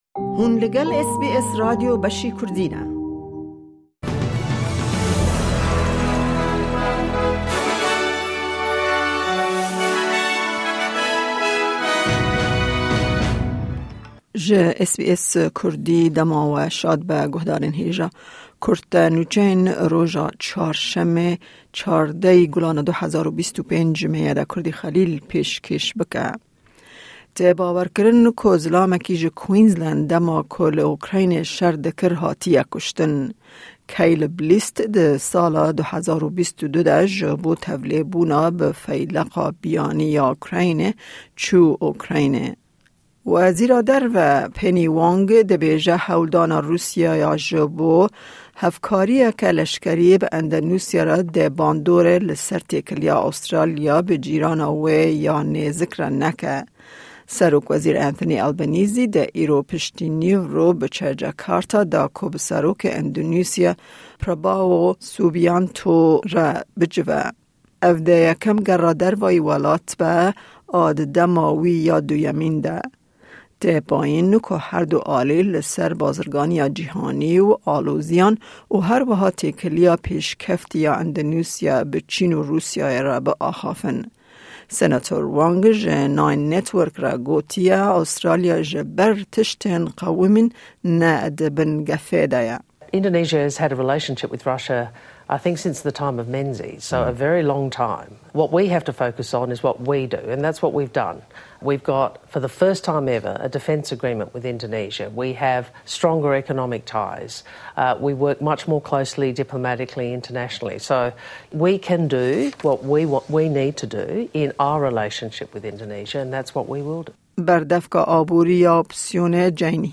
Kurte Nûçeyên roja Çarşemê, 14î Gulana 2025